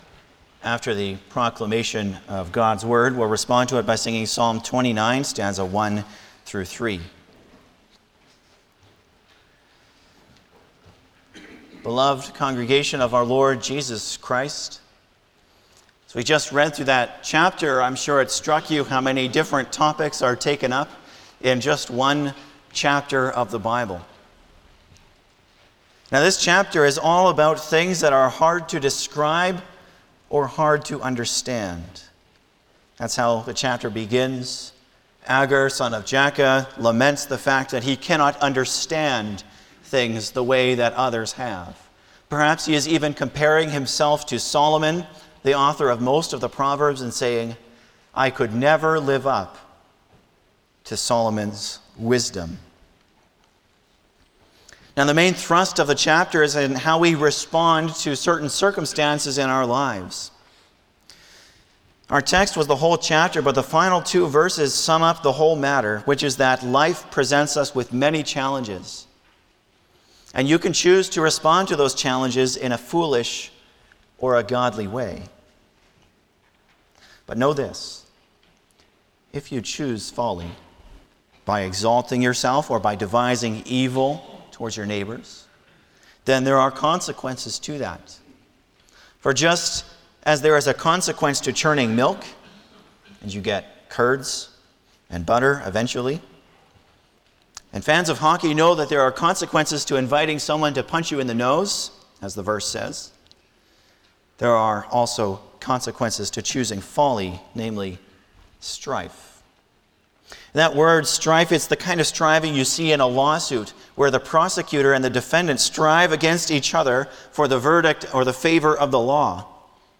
09-Sermon.mp3